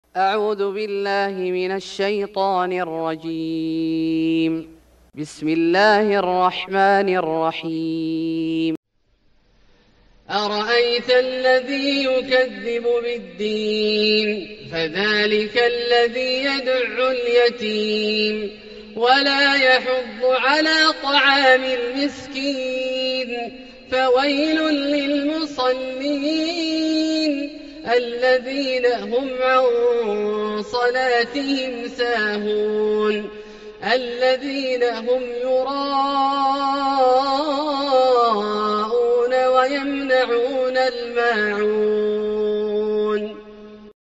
سورة الماعون Surat Al-Maun > مصحف الشيخ عبدالله الجهني من الحرم المكي > المصحف - تلاوات الحرمين